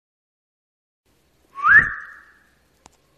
哨子2 男性
描述：男哨
标签： 口哨
声道立体声